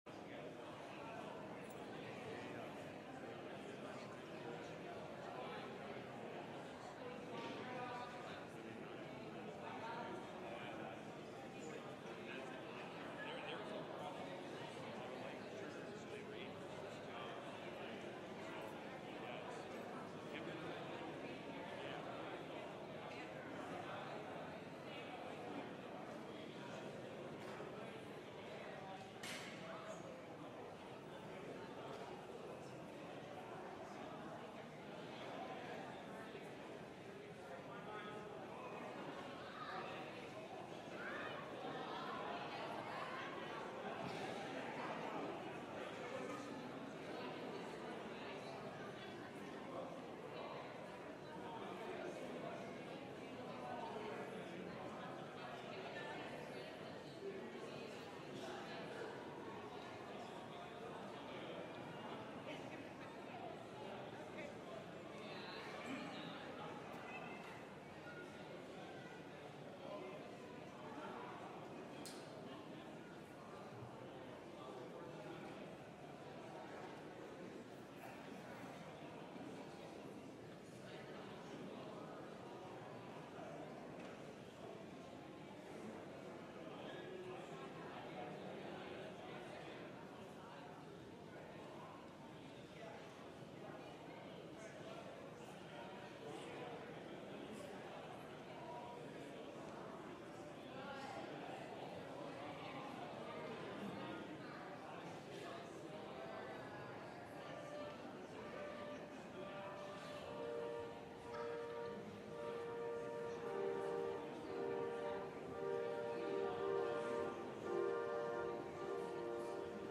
LIVE Morning Worship Service - Who am I?